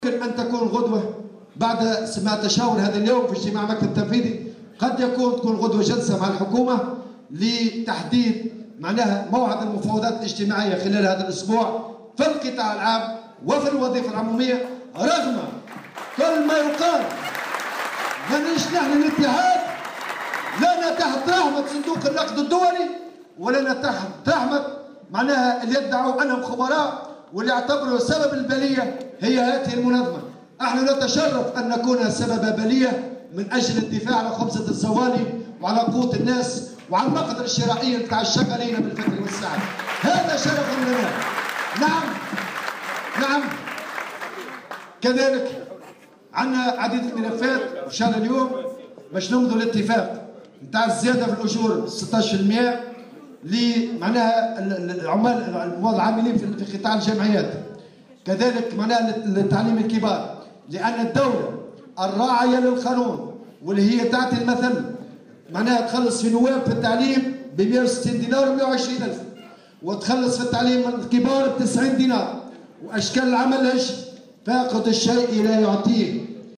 وأضاف الطبوبي في تصريح اليوم لمراسة "الجوهرة أف أم" على هامش المؤتمر العادي 23 للاتحاد الجهوي للشغل بنابل " ما ناش تحت رحمة صندوق النقد الدولي"، مؤكدا أن الاتحاد ماض في الدفاع عن حقوق الشغالين.